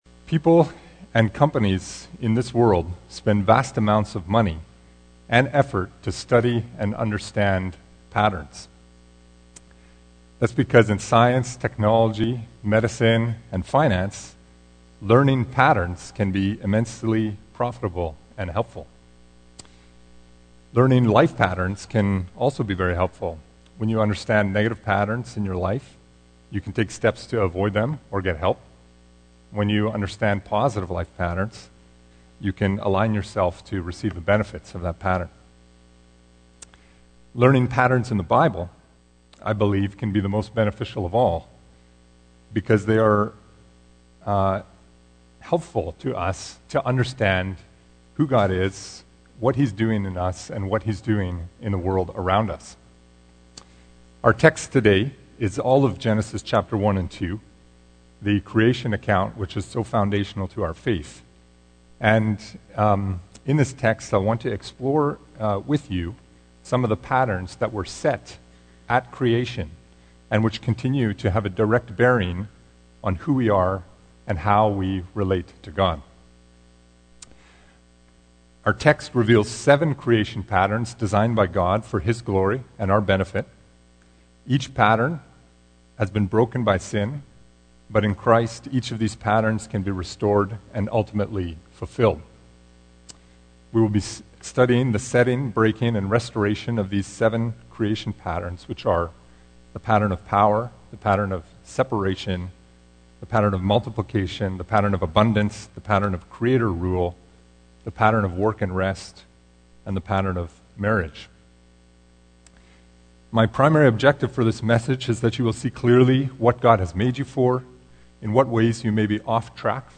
In this message, we learn how each pattern is broken by sinful humanity, but restored […] view sermon